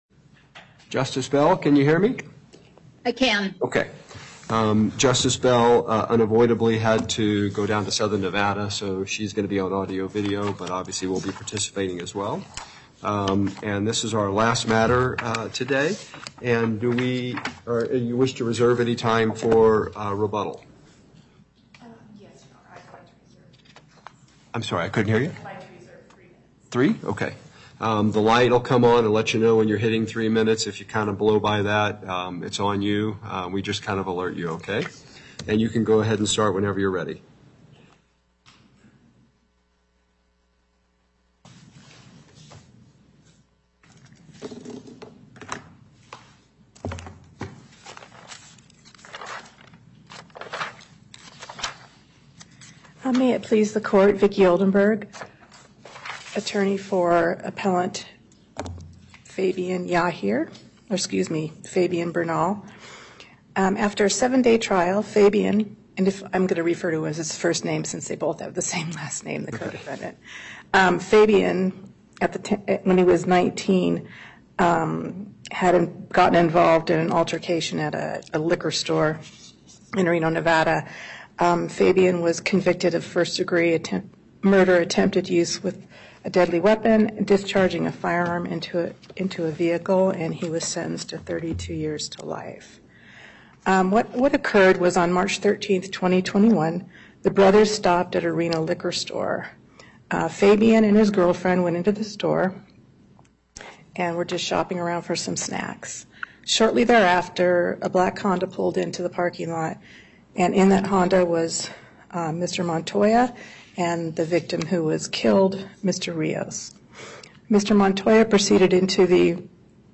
Location: Carson City Before Panel A24, Justice Herndon presiding Appearances